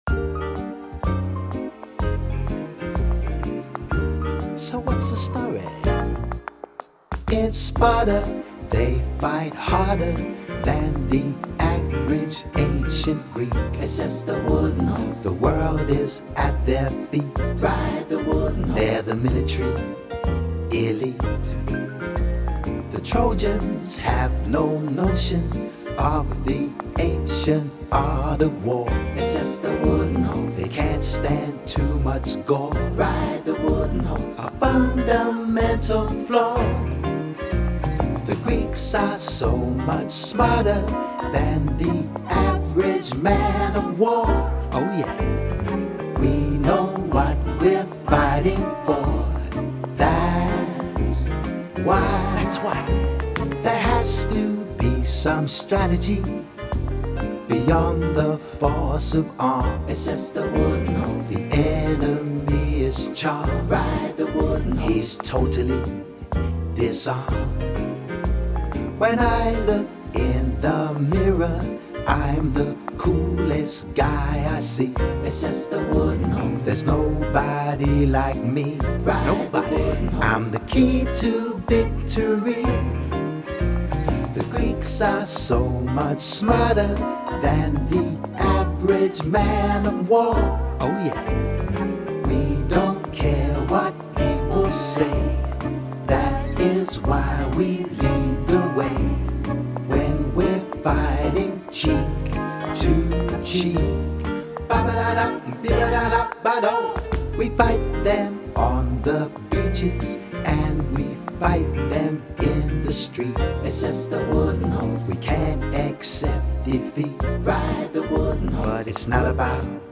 Song style: tap
Sung by: Odysseus (Greek hero) and chorus